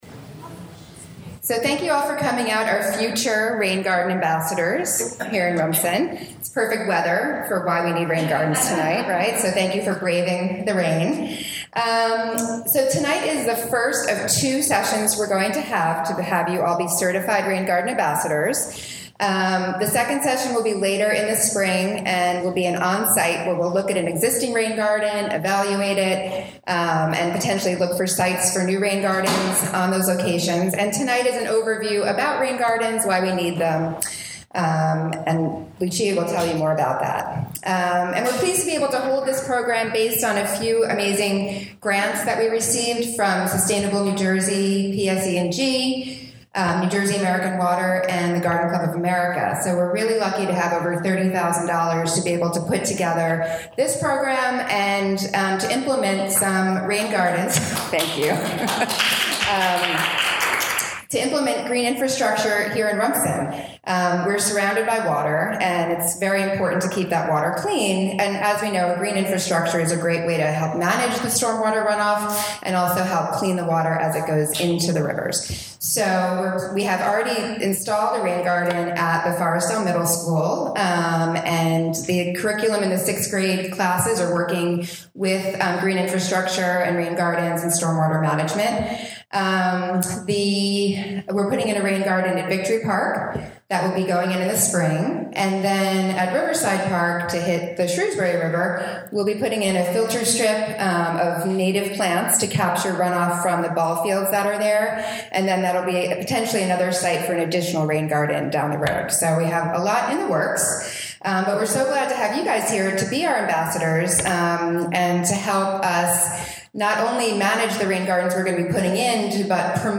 The Rumson Environmental Commission is partnering with American Littoral Society to host a free Rain Garden Ambassador Training Workshops. The first was held on January 25th at Bingham Hall. This workshop is a part of the $30,000 in grants received from Sustainable Jersey, PSEG Foundation and New Jersey American Water to install and promote Green Infrastructure projects in Rumson Borough.